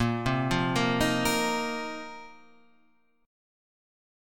Bbsus2sus4 chord